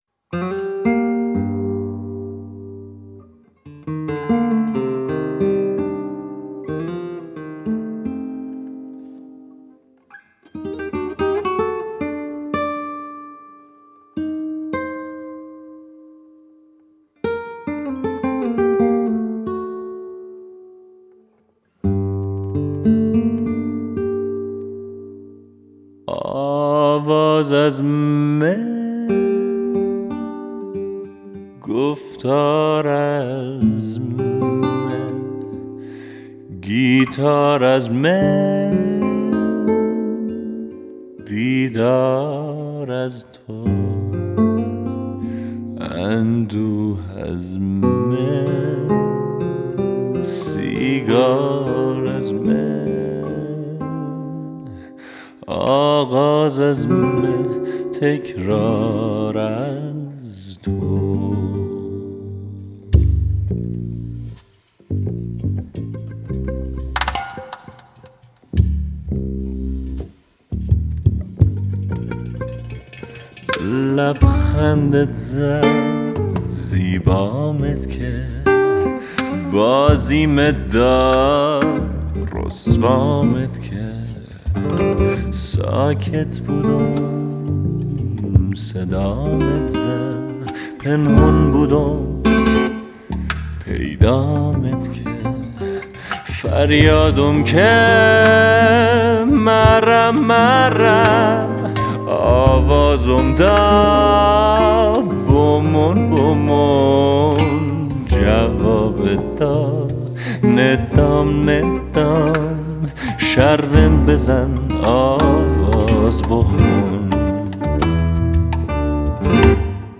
گیتار
پیانو
پرکاشن
گیتارباس